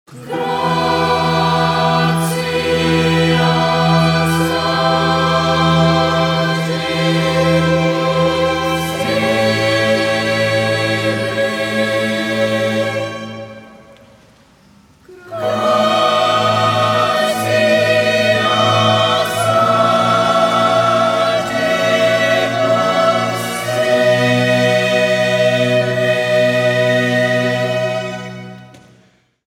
Highbridge Voices performed Vivaldi’s Gloria on December 10, 2015 at Sacred Heart Church in the Bronx.
The 115 choristers range in age from 9-18.
Violin I
Violin II
Viola
Cello
Contrabass
Oboe
Trumpet
Continuo
Soprano
Alto